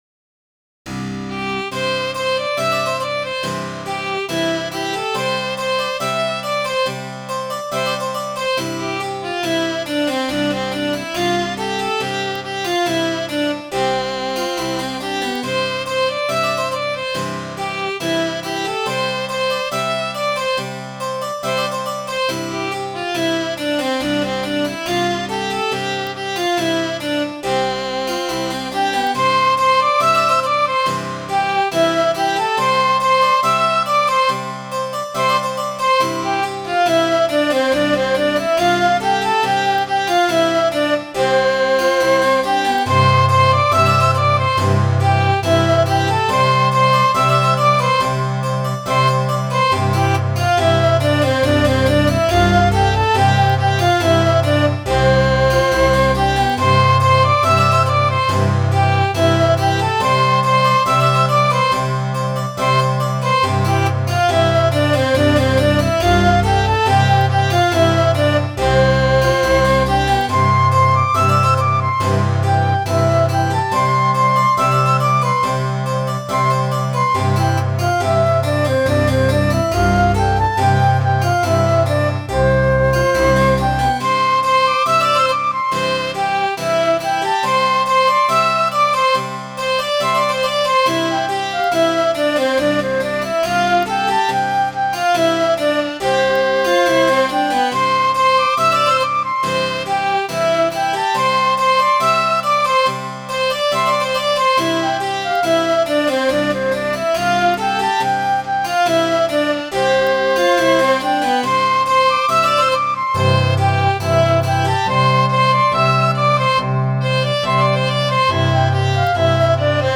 Midi File, Lyrics and Information to Sweet William